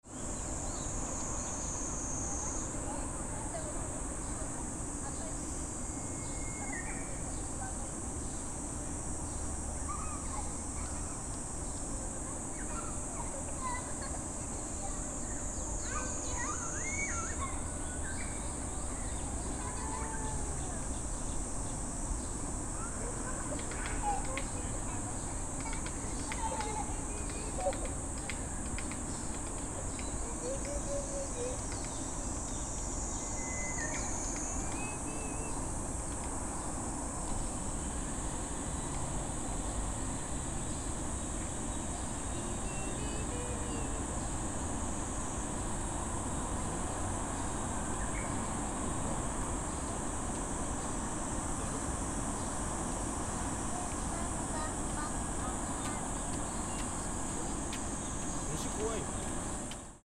Even before the end of the rainy season, cicadas began to make noise in Mt. Shinobu Park.
♦ Some families were playing at Mt. Shinobu Park on such a hot day.